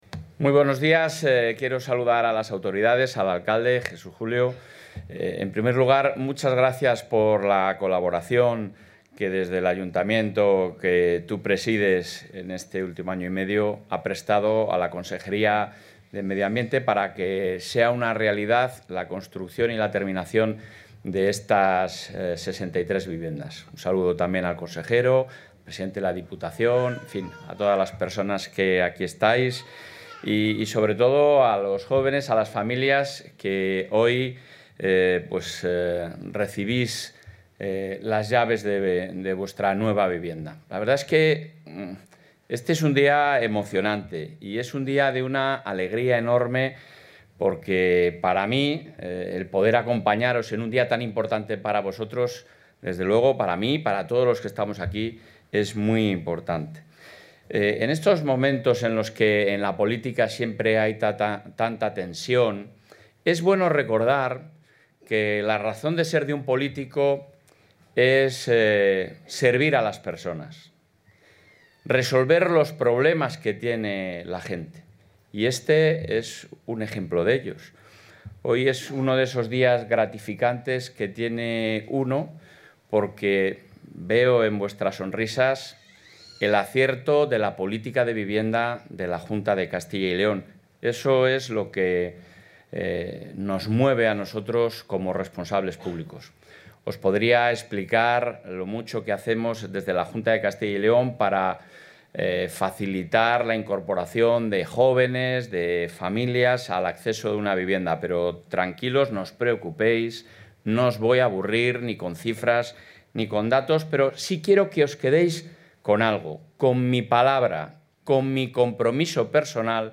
Intervención del presidente de la Junta.
El presidente de la Junta de Castilla y León, Alfonso Fernández Mañueco, ha asistido a la entrega de las viviendas rehabilitadas del edificio Puente Colgante en Valladolid, destinadas al alquiler para familias jóvenes, que han supuesto un coste total de más de 7,2 millones de euros, cofinanciados con fondos europeos.